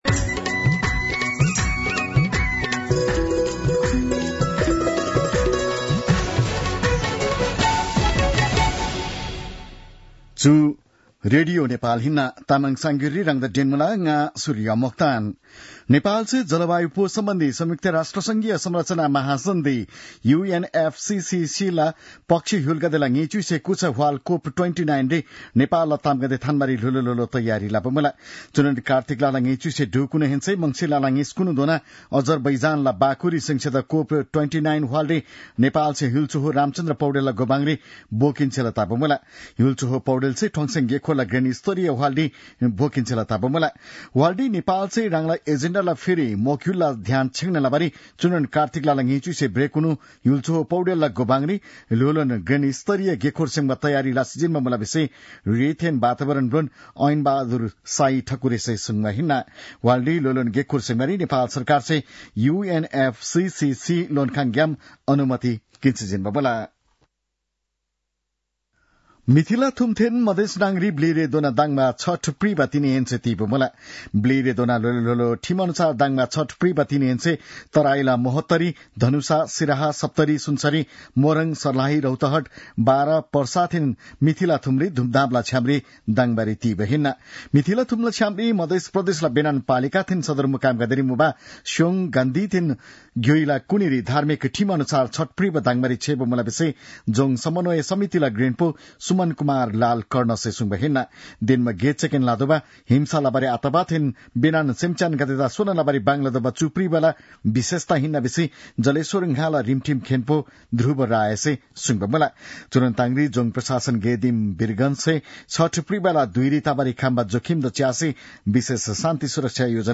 तामाङ भाषाको समाचार : २१ कार्तिक , २०८१